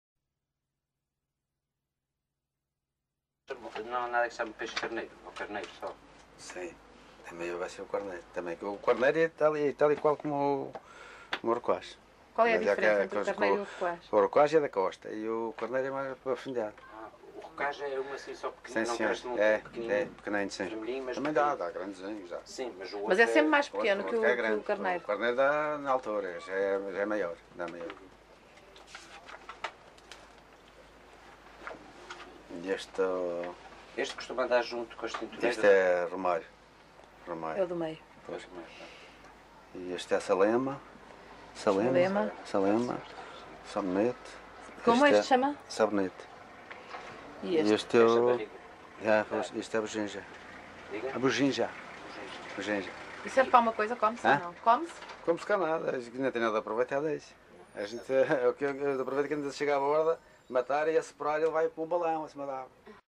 LocalidadeCaniçal (Machico, Funchal)